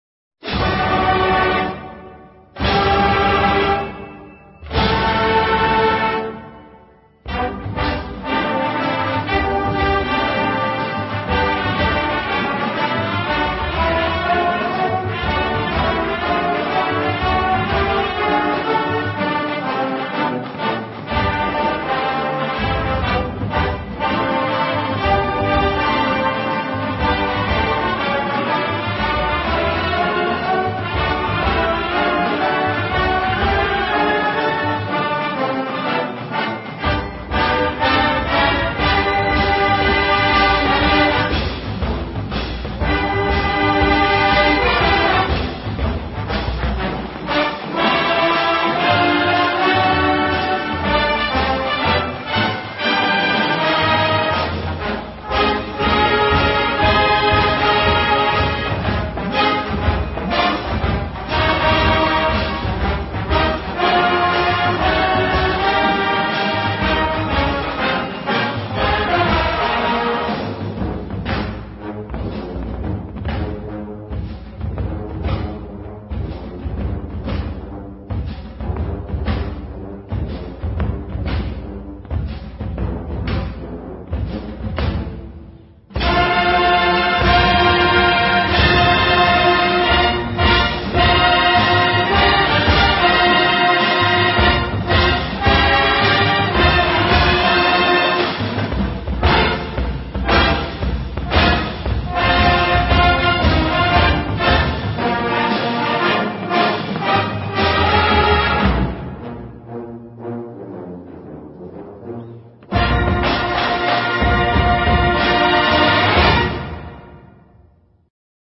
"Devil With a Blue Dress," or Virginia Tech's woozy take on the